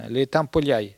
Maraîchin
Patois
Catégorie Locution